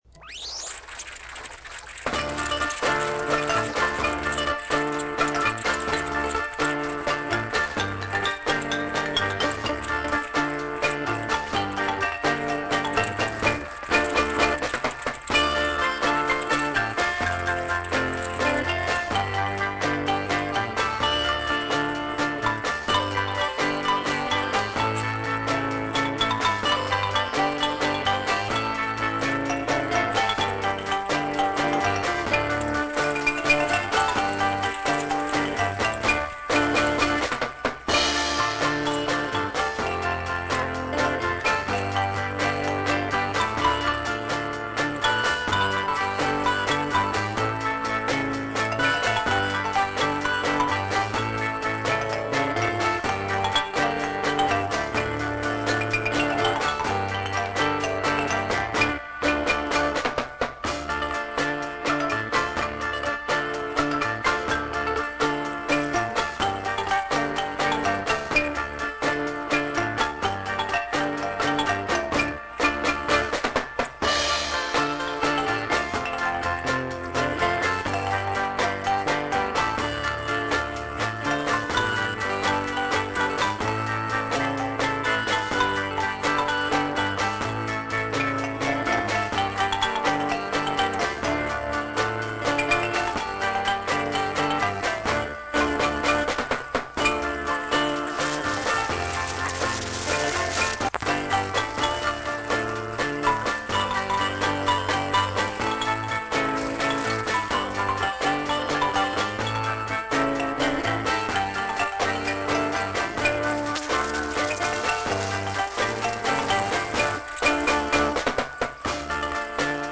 Cambodian tune as located on wire spool